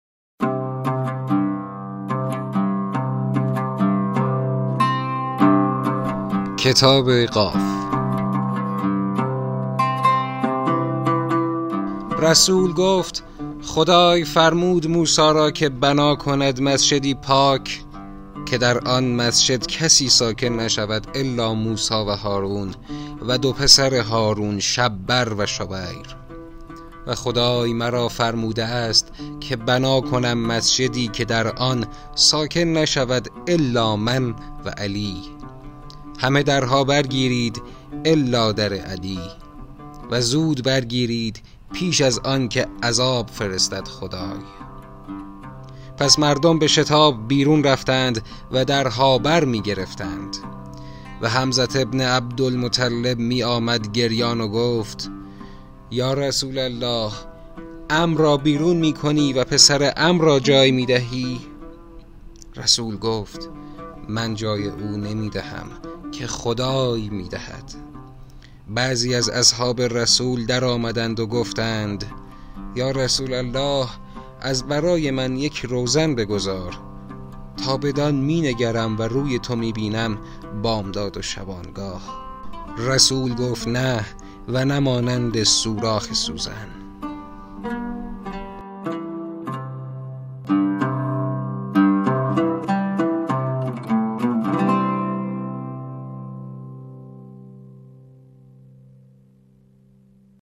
در این بخش متنی از کتاب «شرف‌النبی» انتخاب شده است